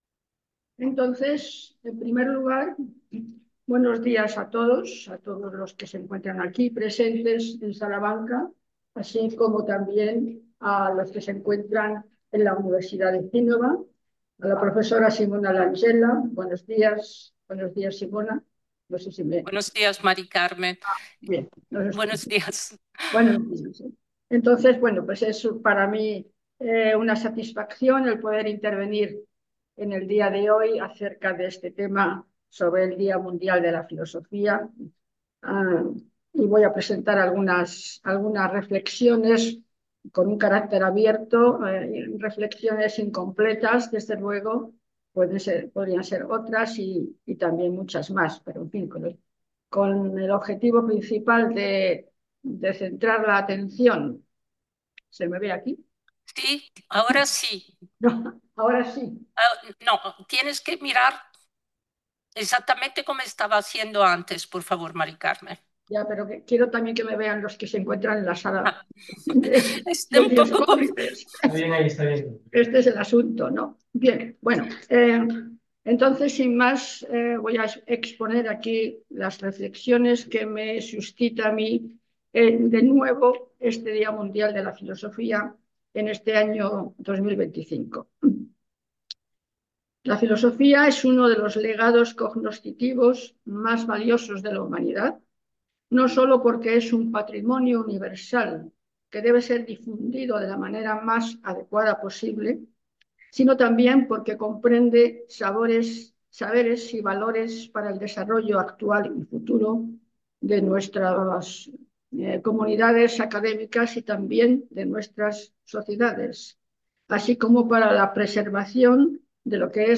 Conferenza Integrale - La filosofía y los límite del presente Parte 2